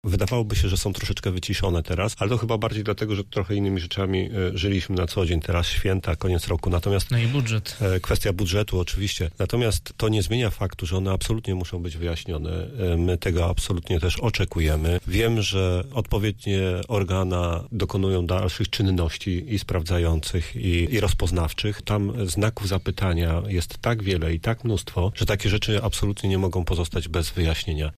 Dziś w Rozmowie Punkt 9 poprosiliśmy Piotra Barczak, przewodniczącego Rady Miasta Zielona Góra, o podsumowanie ostatnich dwunastu miesięcy pracy samorządu.